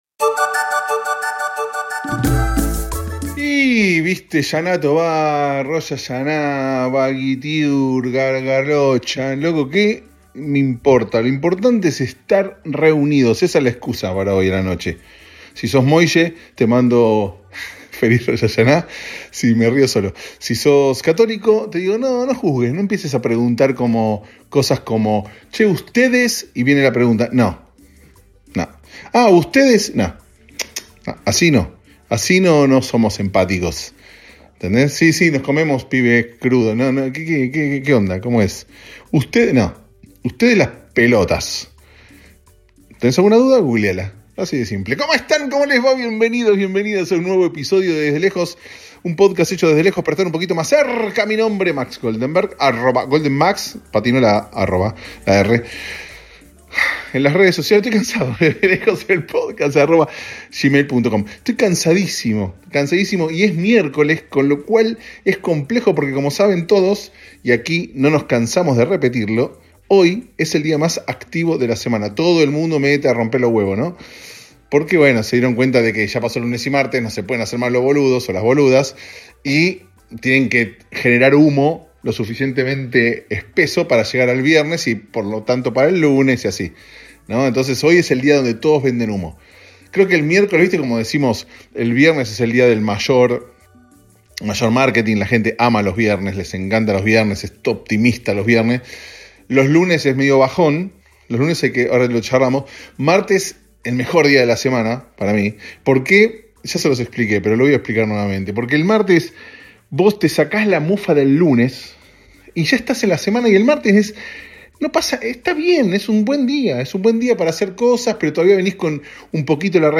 En medio de una oficina, mientras espero, se graba este episodio tan pero tan lindo que se los recomiendo enormemente (si yo no lo hago yo...) Hablamos de judaísmo, de médicos llamando de la nada, escuchamos canción pop y debatimos sobre cómo hay que decirle a Brad Pitt...